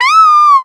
Cries
STUFFUL.ogg